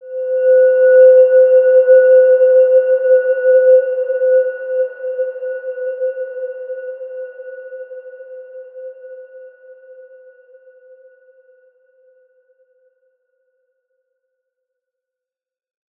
Evolution-C5-mf.wav